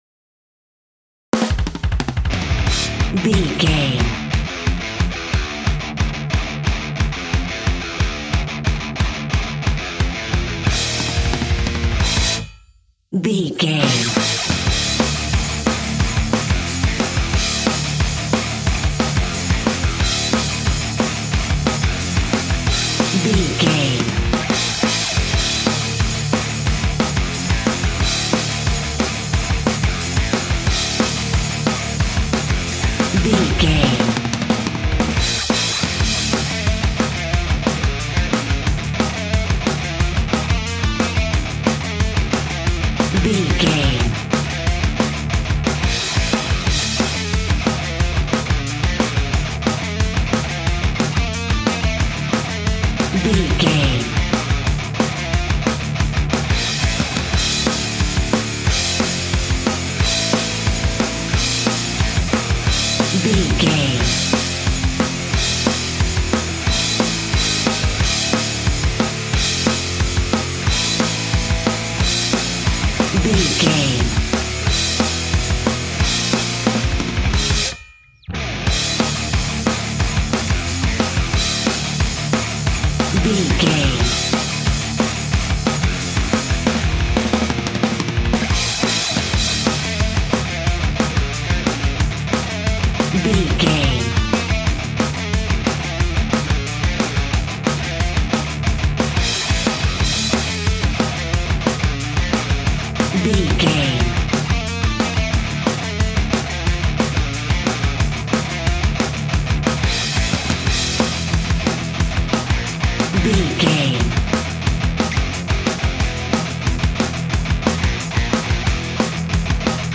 Epic / Action
Fast paced
Aeolian/Minor
Fast
hard rock
heavy metal
distortion
Rock Bass
heavy drums
distorted guitars
hammond organ